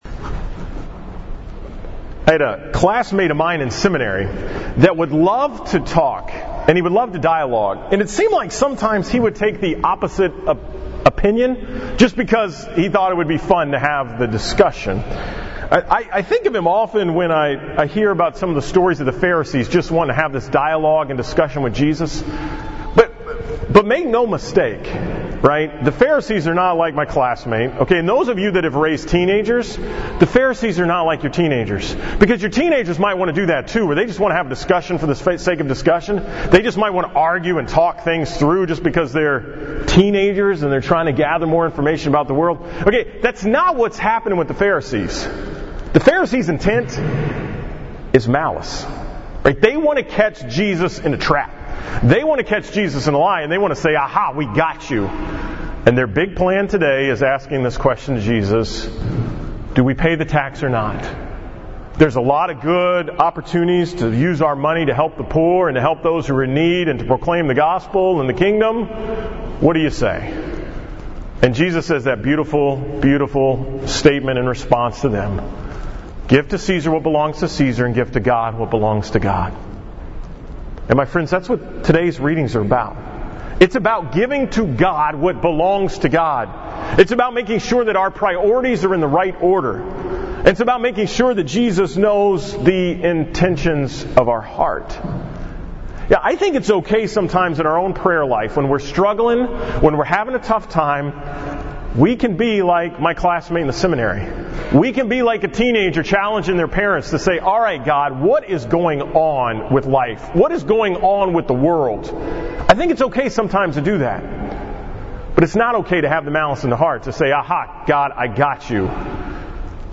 From the 9:30 am Mass at Annunciation